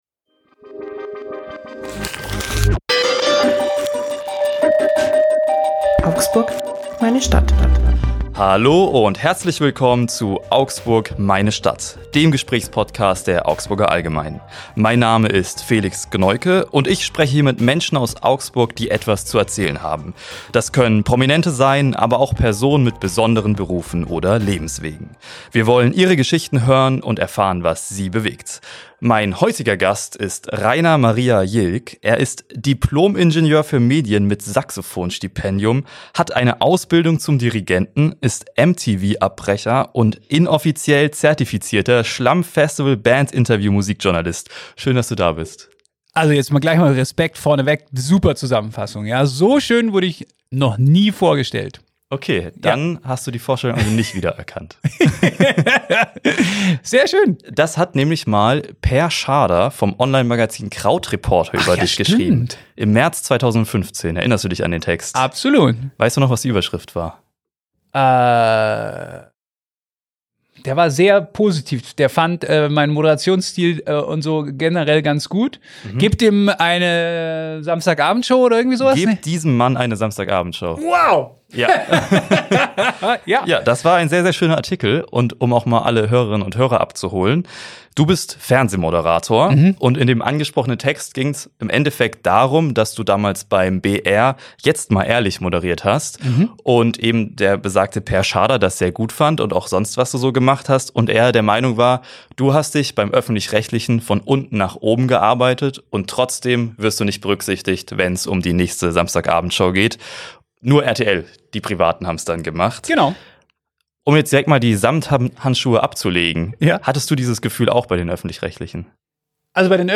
Im Interview-Podcast „Augsburg, meine Stadt“ spricht er über seine Anfänge im Medienbereich und seine Leidenschaft für Musik. Er gibt Einblicke in die Herausforderungen und Freuden des Moderatorenlebens und reflektiert über die Veränderungen in der Medienlandschaft.